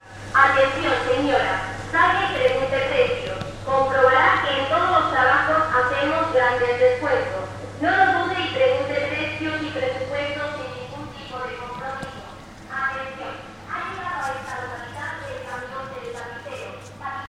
描述：Spanish news reader is overpowered by radio noise/static and some words are uninteligible.
标签： man voice Spanish radio noise TwenteUniversity AM uninteligible male